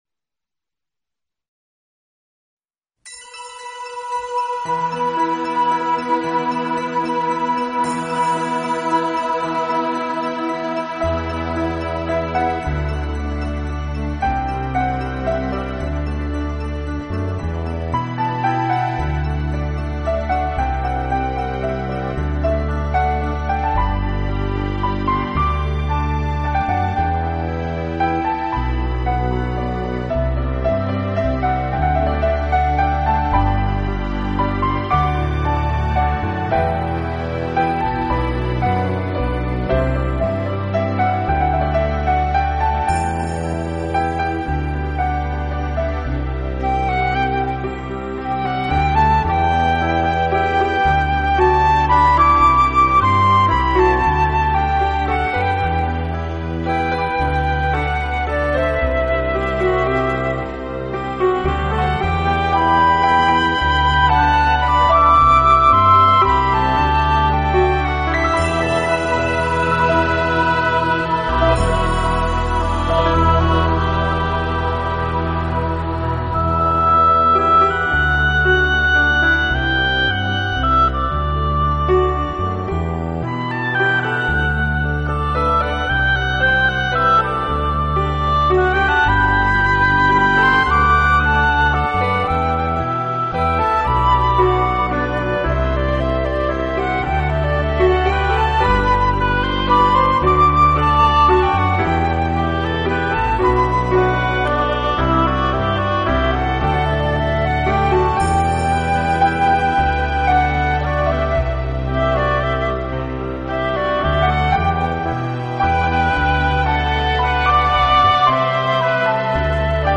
Synthesizer, Piano